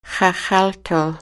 Listen to the elders